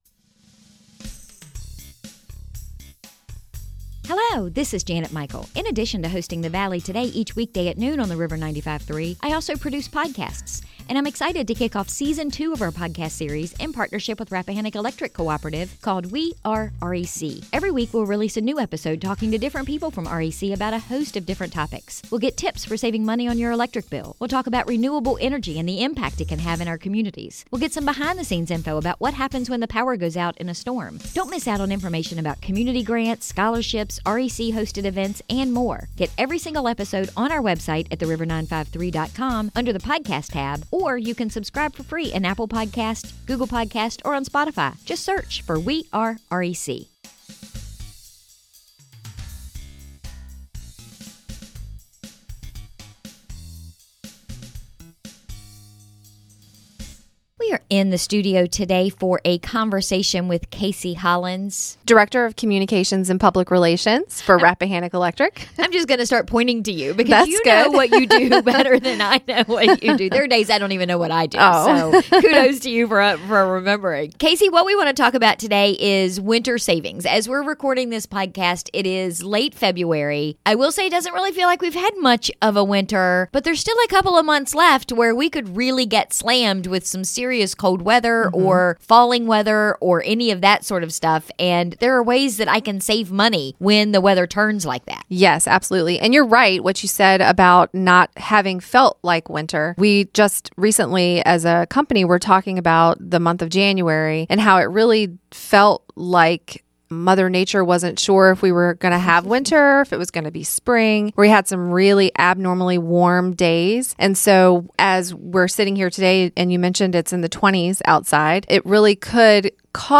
We were in the studio